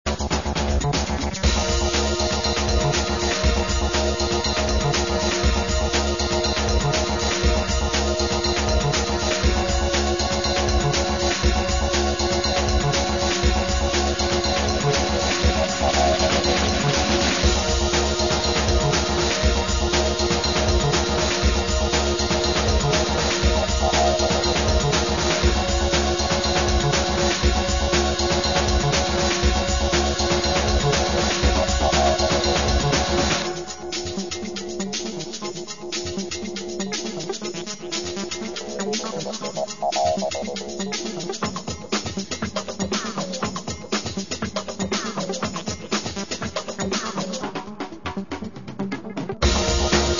Tercera maqueta con estilo bailable y ritmos rápidos.